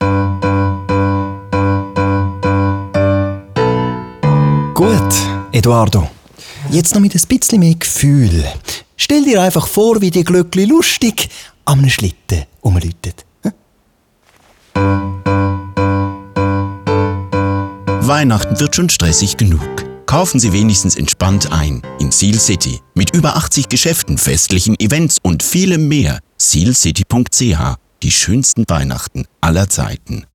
Neue Radiospots für das Einkaufszentrum Sihlcity.